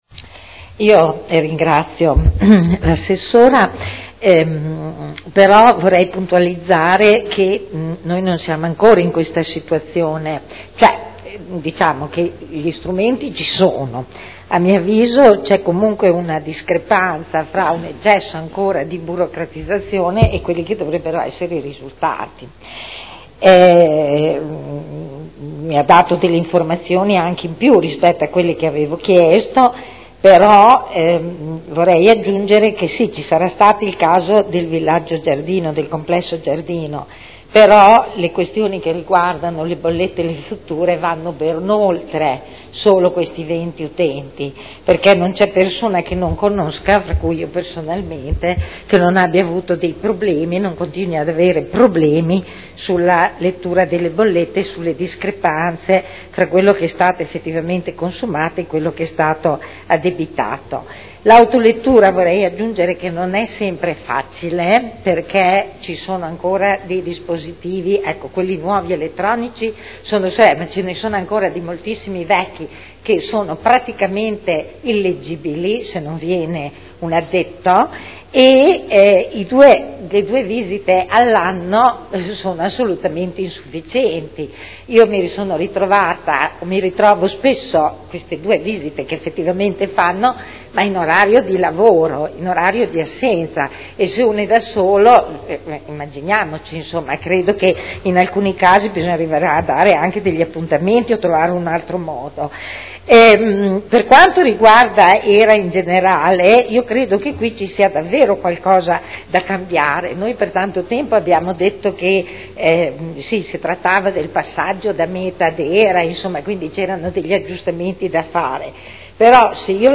Seduta del 16/05/2011. Conclude interrogazione della consigliera Rossi E. (IdV) avente per oggetto: “Bollette HERA”